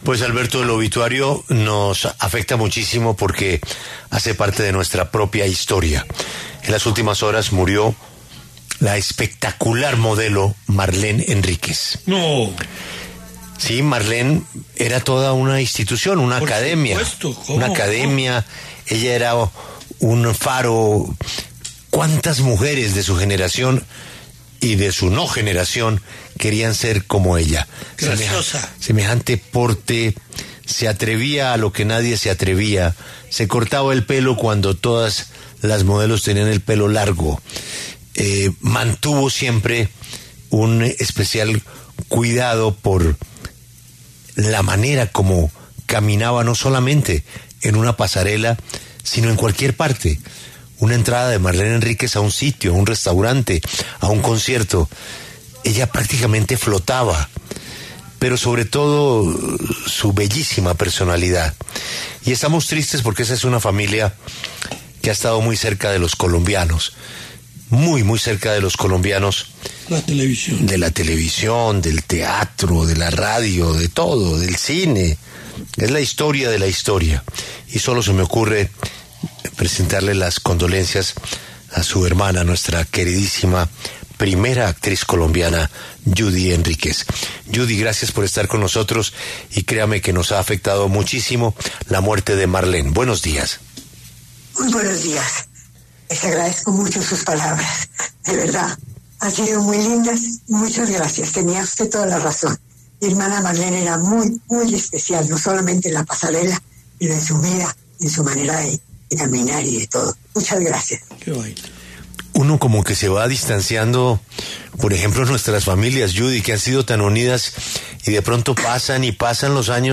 En La W, la actriz Judy Henríquez habló sobre el legado de su hermana Marlene Henríquez.